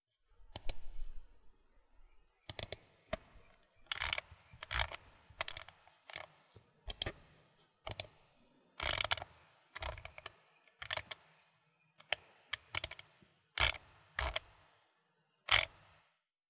mouse-clicks-scrolls.wav